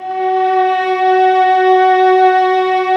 Index of /90_sSampleCDs/Roland LCDP13 String Sections/STR_Violas II/STR_Vas4 Amb p